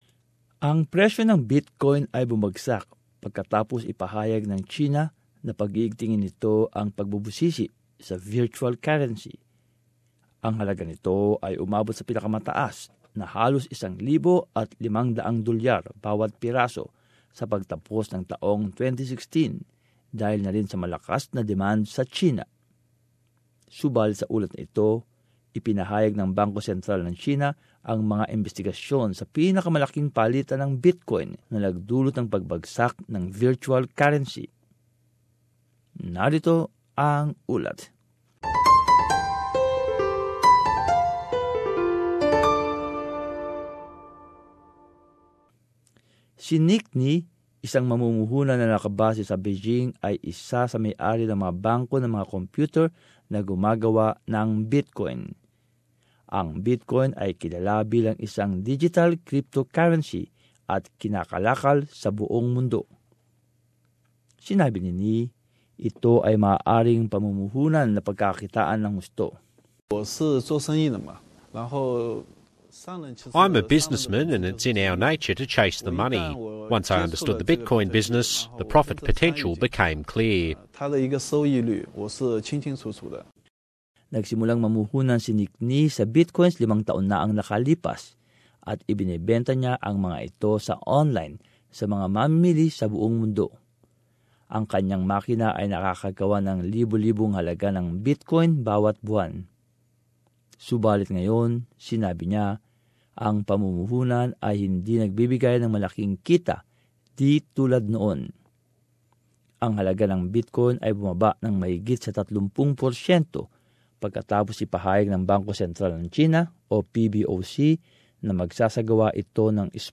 But in this report, China's central bank has announced investigations into the country's biggest Bitcoin exchanges - causing the currency to plunge.